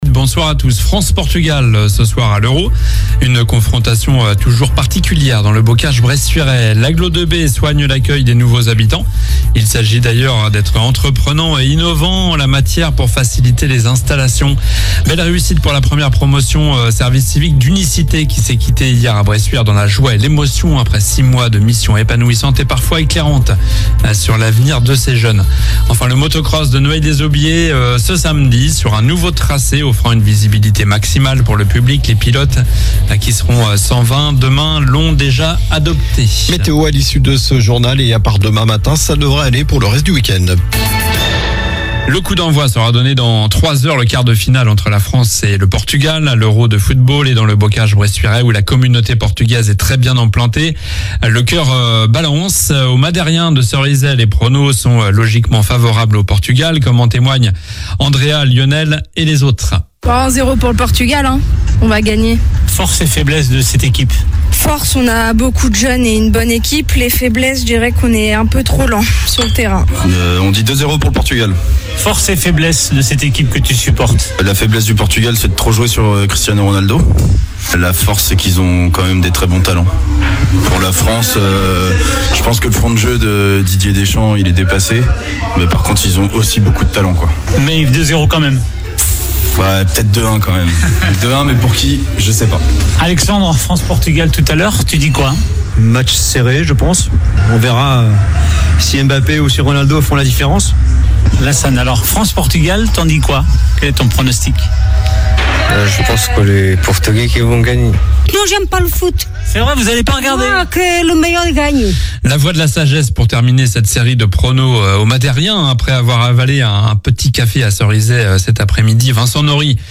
Journal du vendredi 05 juillet (soir)